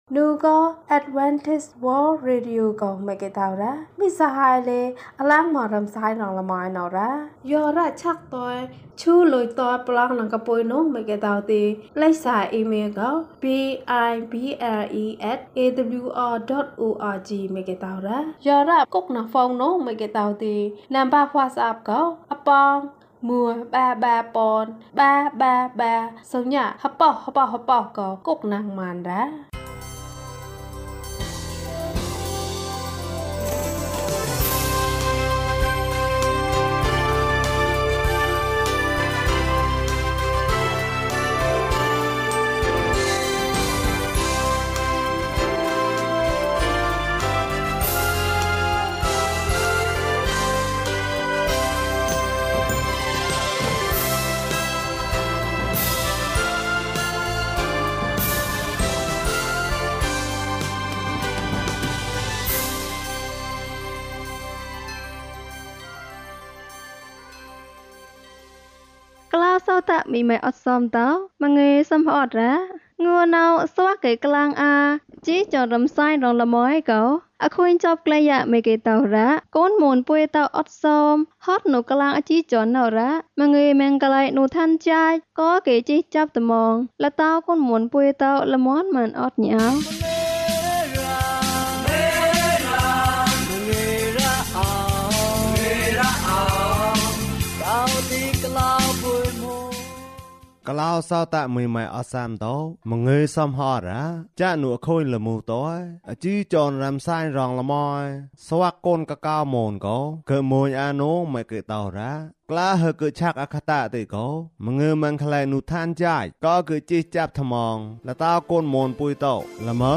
(ည:မဍောၚ်ၜိုတ်ဂှ်ကၠုၚ်ဋ္ဌာန်အဴညိ) ကျန်းမာခြင်းအကြောင်းအရာ။ ဓမ္မသီချင်း။ တရားဒေသနာ။